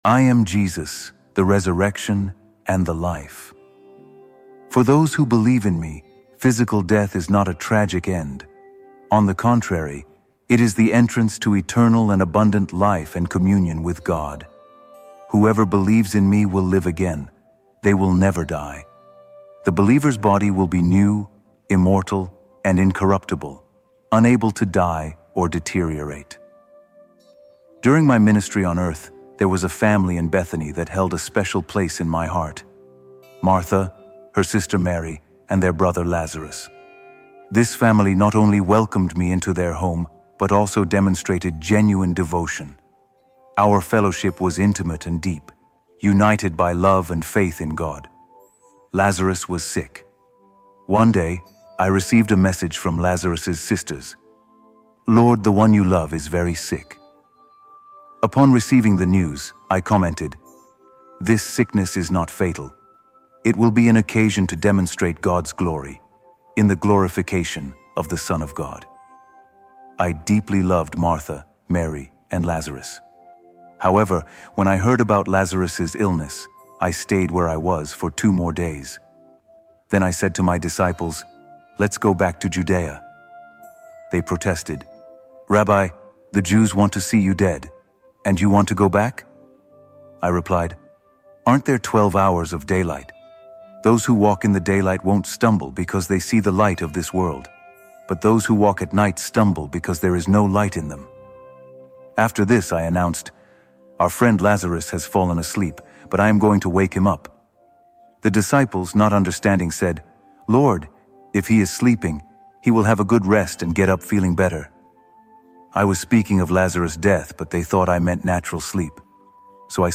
All advertisements are placed at the very beginning of the episode so nothing interrupts the experience once the story begins.
Each episode of The Bible Stories: Words of Life feels like sitting beside a quiet storyteller who knows something about the human heart.